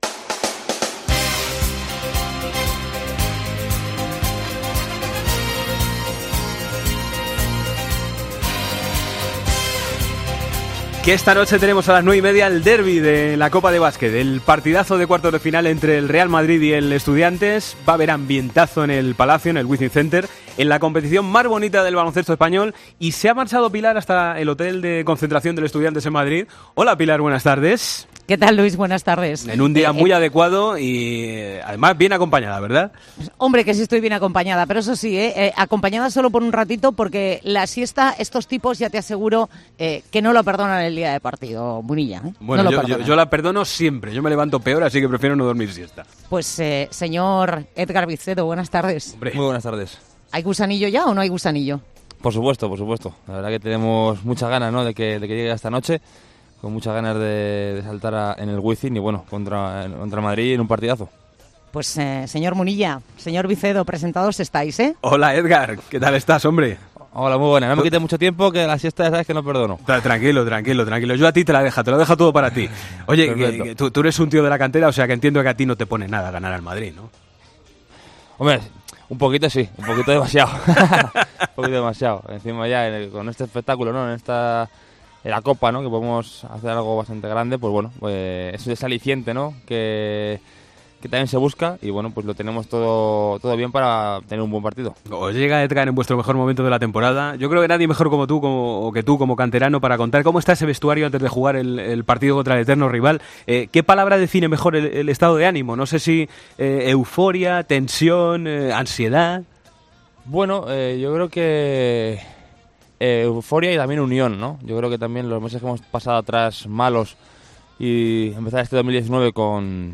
Hablamos con el jugador de Movistar Estudiantes del partido copero con el Madrid.